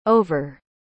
En inglés británico se dice /ˈəʊ.vər/ y en inglés americano suena como /ˈoʊ.vɚ/. La primera sílaba va con fuerza, y la r al final suena más marcada en el acento americano.
• La «o» suena como un «ou» de no.